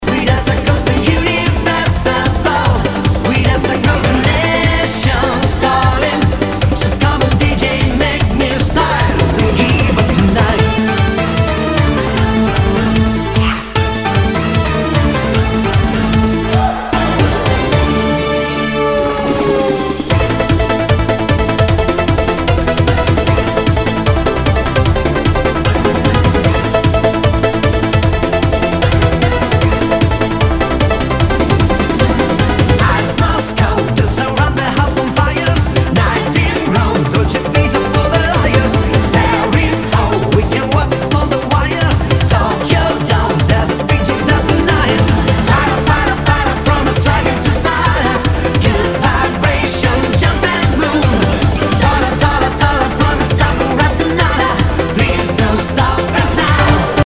HOUSE/TECHNO/ELECTRO
ナイス！ユーロビート！
プレイ可能ですが盤に歪みあり。